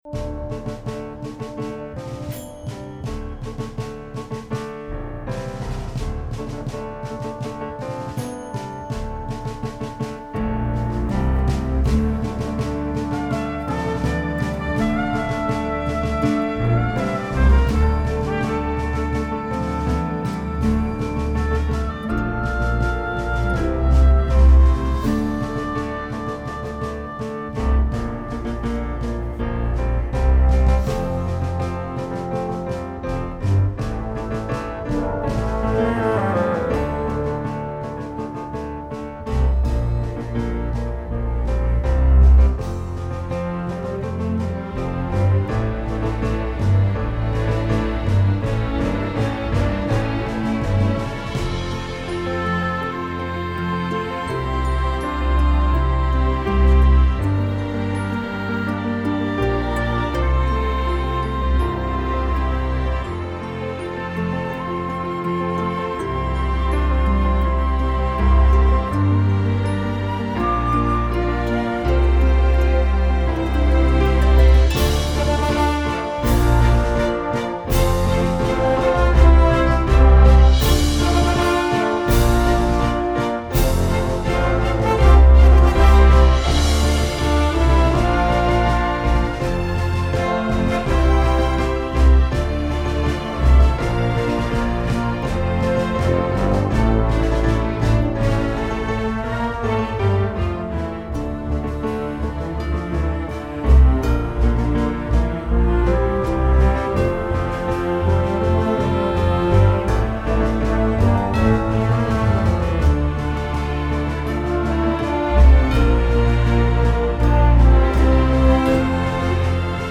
Мінусовка